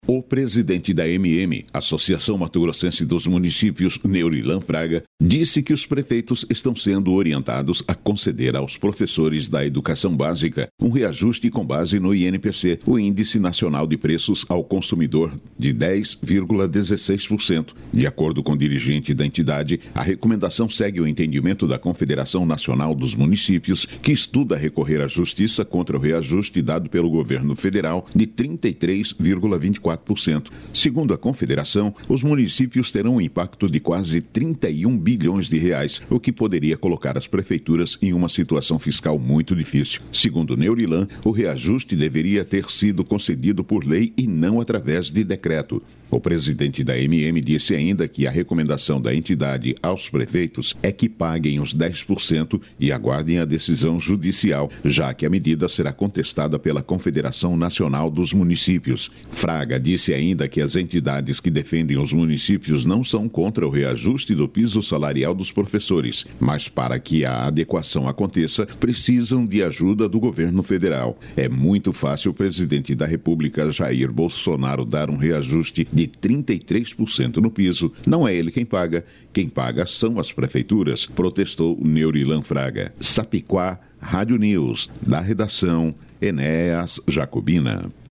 Boletins de MT 12 fev, 2022